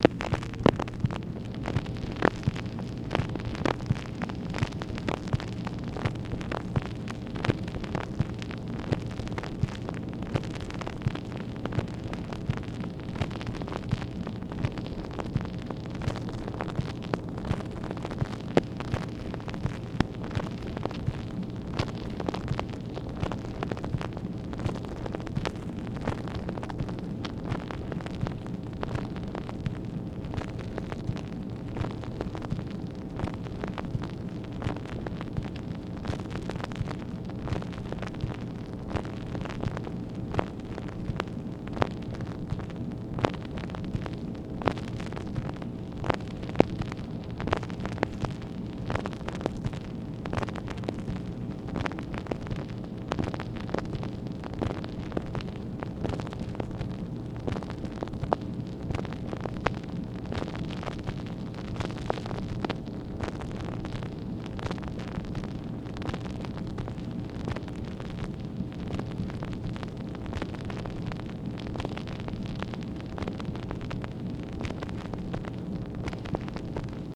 MACHINE NOISE, August 21, 1964
Secret White House Tapes | Lyndon B. Johnson Presidency